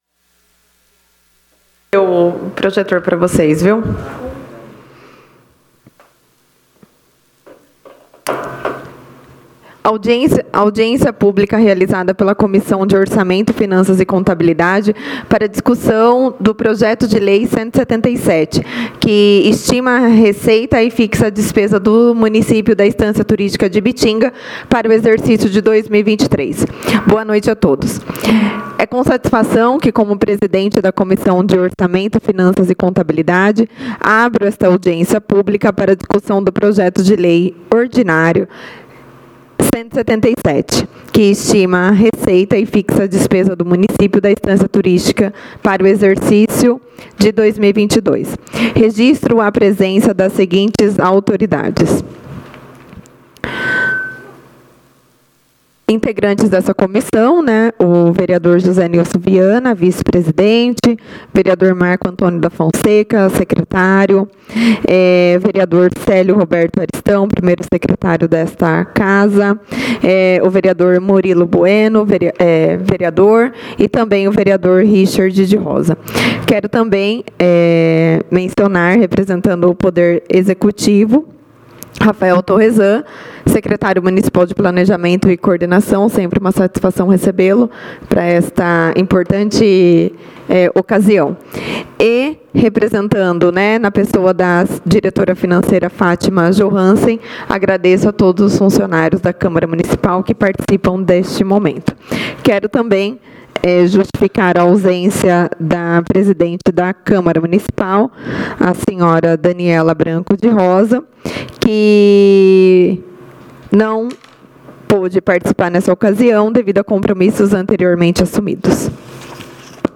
7ª Audiência Pública, de 11/11/2022